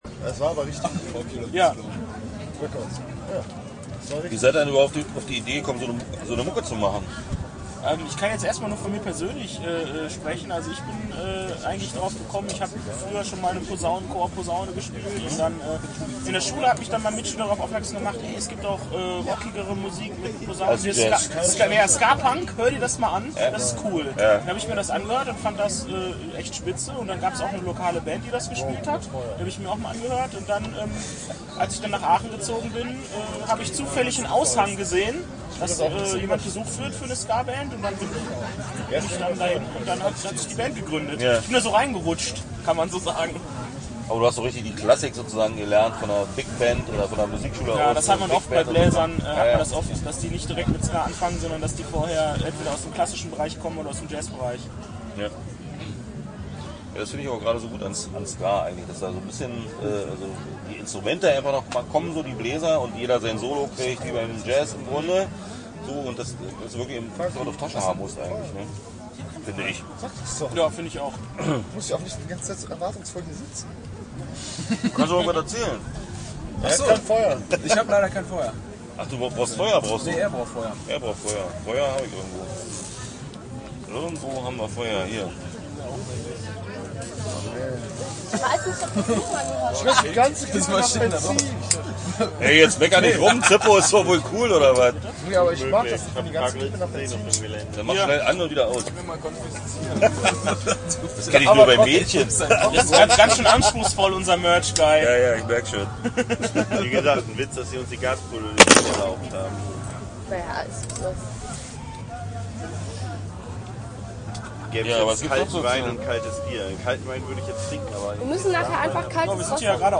Start » Interviews » Cocoheadnuts
Das Interview fand in Rosslau auf dem Zeltplatz des "This is Ska" Festivals statt.